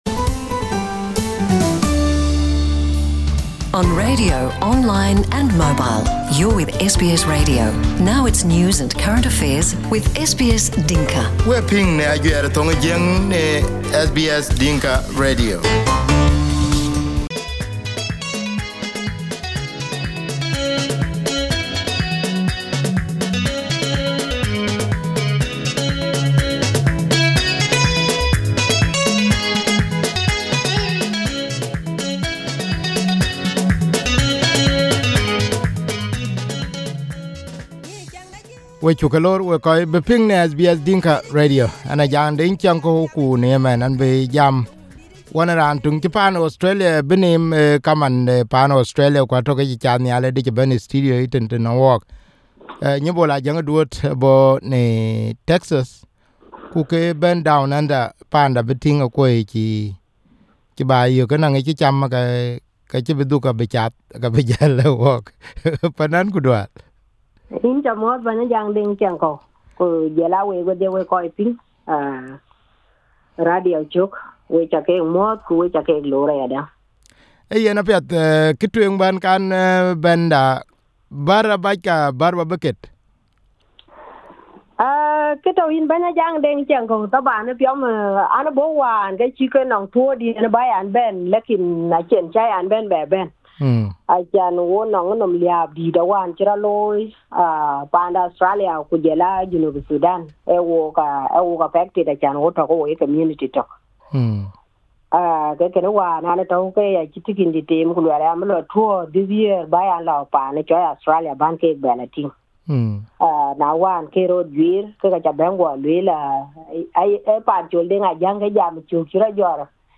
We interview her while she was in Brisbane.